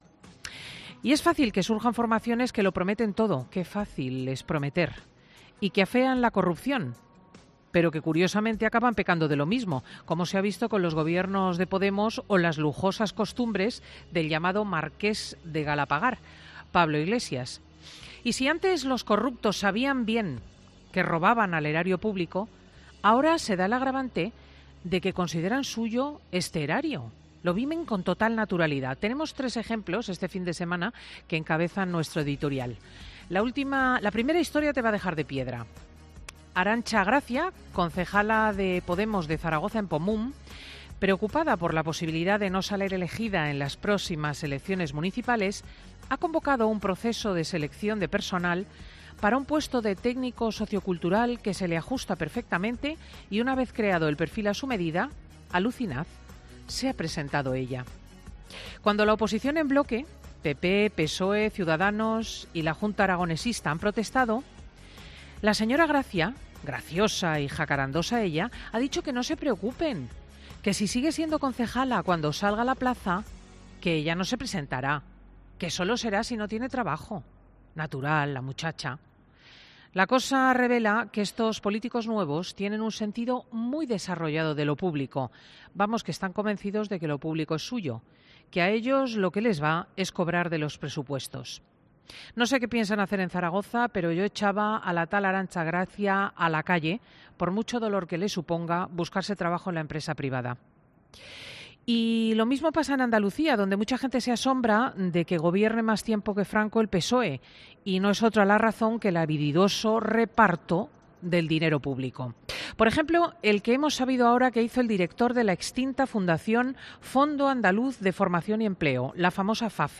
En su monólogo al comienzo del programa 'Fin de Semana', Cristina López Schlichting ha repasado algunos de los casos de corrupción que más la han escandalizado en los últimos días.